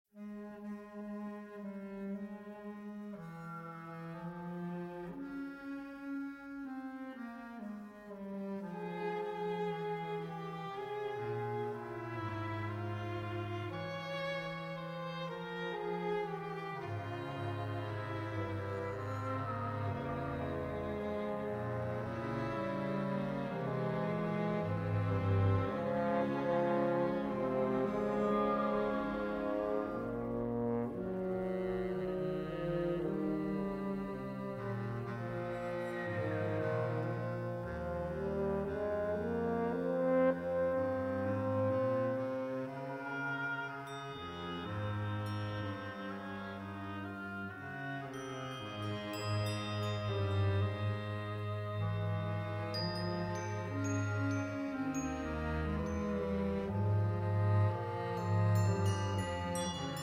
Multiple Percussion